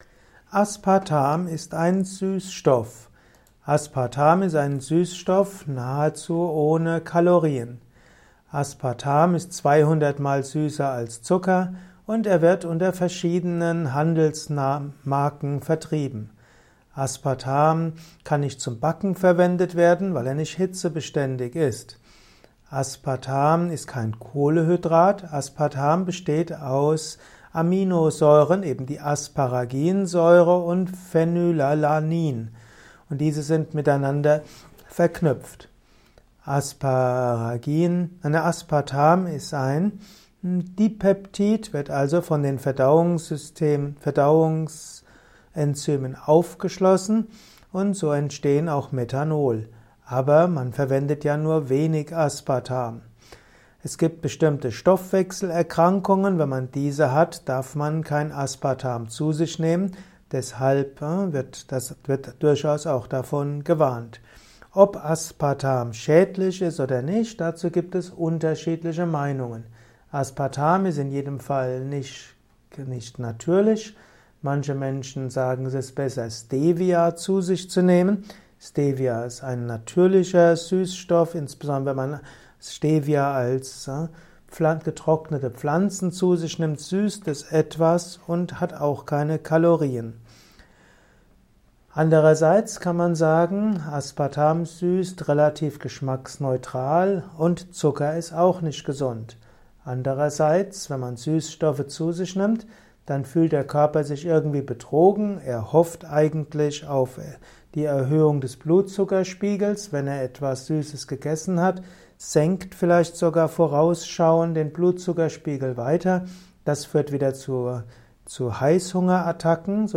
Kompakte Informationen zu Aspartam in diesem Kurzvortrag